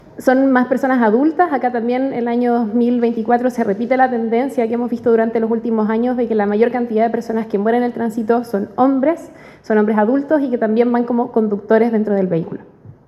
Por último, la secretaria Ejecutiva de Conaset, Luz Renata Infante, desglosó datos tales como el género, horarios y periodos en que se presentan mayores accidentes viales.